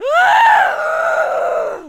general / combat / creatures / alien / she / prepare1.ogg